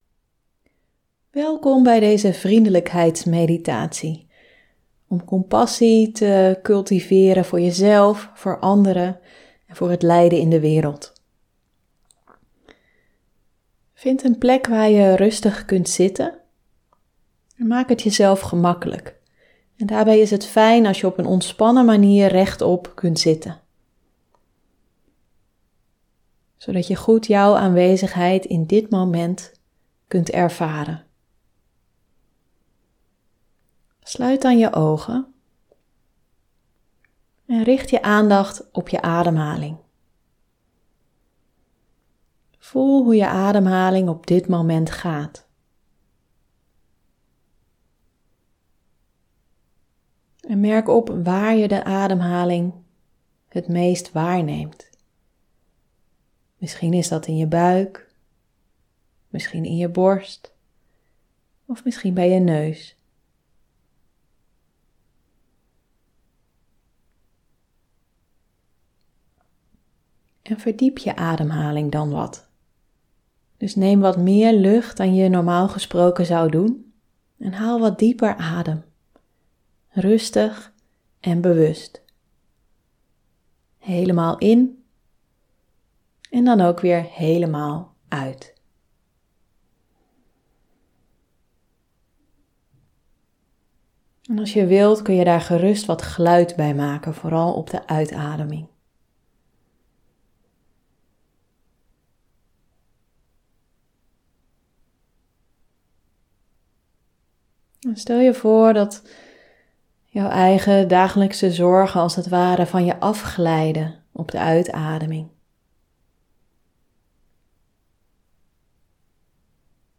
Deze keer een normale podcast aflevering met daarna een uitgebreide compassie meditatie, waarin je je compassie voor jezelf, anderen en de wereld vergroot zonder daarbij zelf uit balans te raken.
Compassie-meditatie.mp3